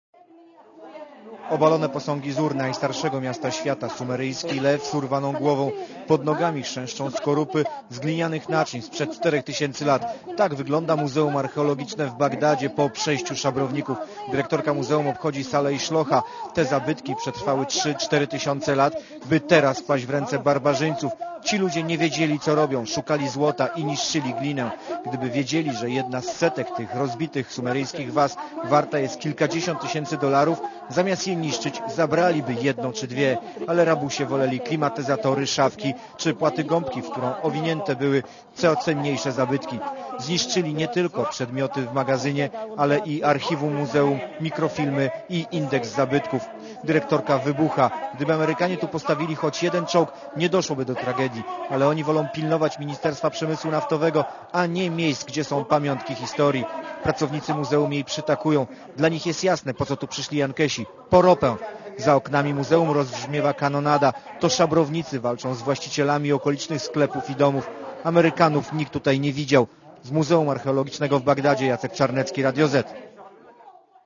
Na miejscu był specjalny wysłannik Radia Zet